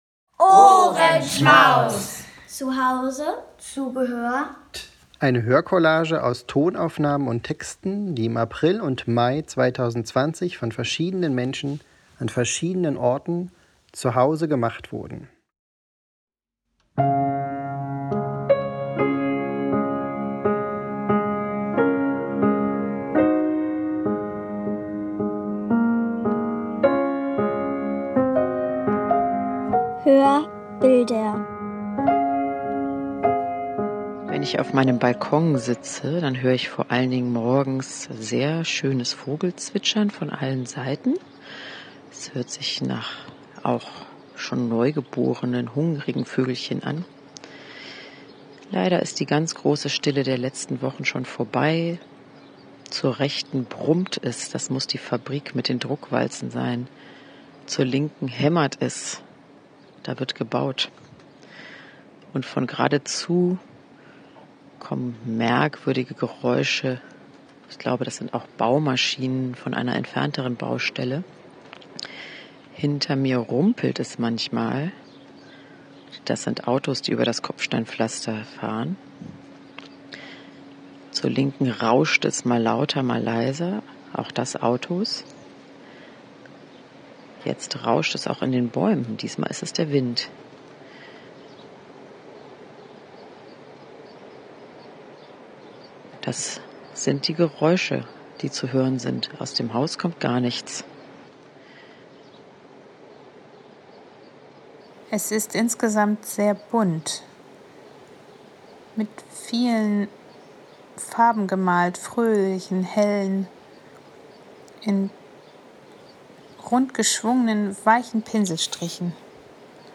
Wir sind zur Ruhe gekommen und haben den Geräuschen, Tönen und Stimmen in unserer zur Zeit begrenzten Umgebung zu Hause genau zugehört und unseren Gedanken dazu freien Lauf gelassen. Wir haben uns dafür nicht getroffen, aber einander trotzdem zugehört und uns in Form von Geräuschen, Tönen und Texten gegenseitig geantwortet. Aus unseren Tonaufnahmen ist eine Hörcollage entstanden.